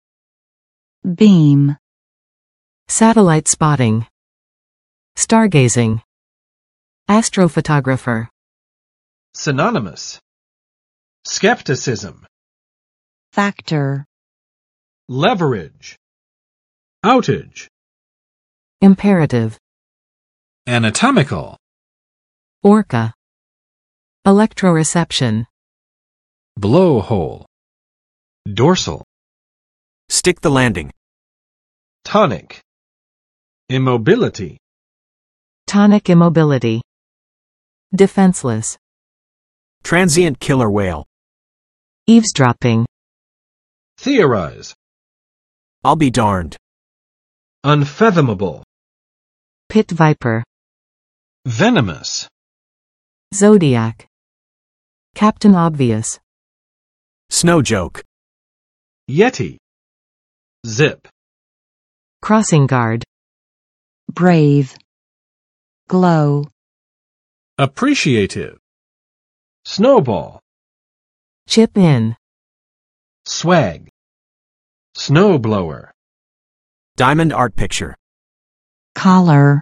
[bim] v.（定向）播送，发送